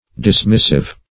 dismissive - definition of dismissive - synonyms, pronunciation, spelling from Free Dictionary
Dismissive \Dis*miss"ive\, a.